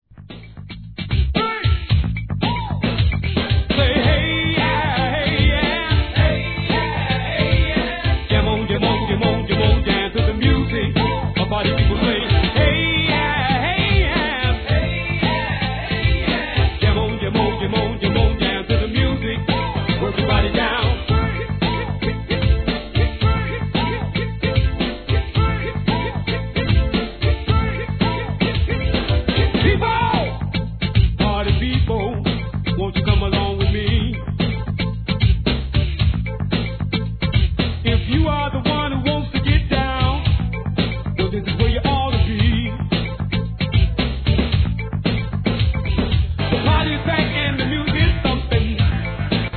HIP HOP/R&B
1991年、DANCE 〜 HIP HOUSE.